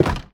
Minecraft Version Minecraft Version 1.21.5 Latest Release | Latest Snapshot 1.21.5 / assets / minecraft / sounds / entity / armorstand / break3.ogg Compare With Compare With Latest Release | Latest Snapshot